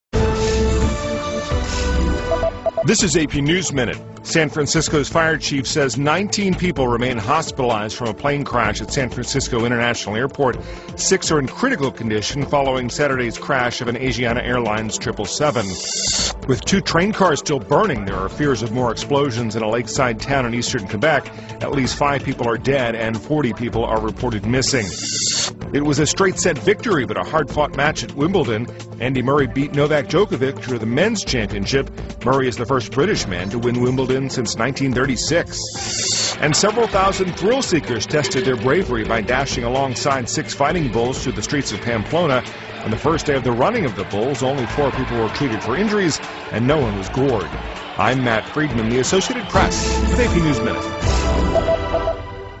在线英语听力室美联社新闻一分钟 AP 2013-07-10的听力文件下载,美联社新闻一分钟2013,英语听力,英语新闻,英语MP3 由美联社编辑的一分钟国际电视新闻，报道每天发生的重大国际事件。电视新闻片长一分钟，一般包括五个小段，简明扼要，语言规范，便于大家快速了解世界大事。